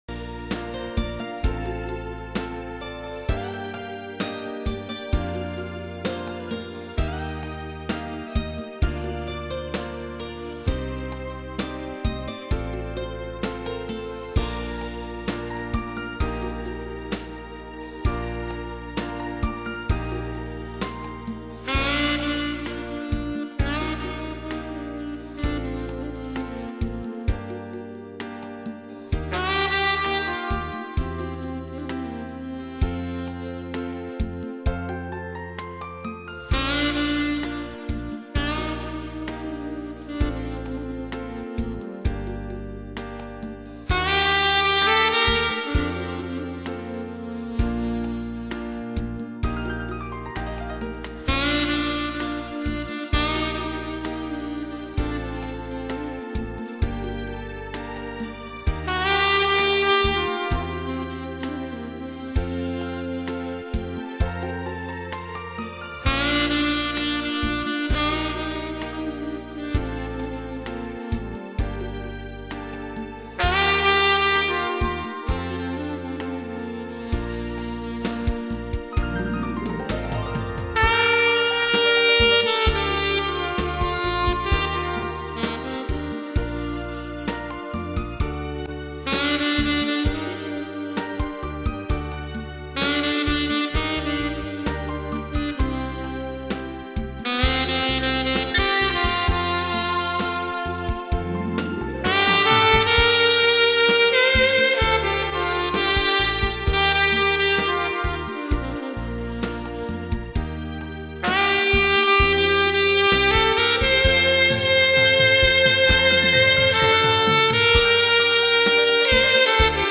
YAS-875커스텀, 듀콥 D8호메탈, 라보즈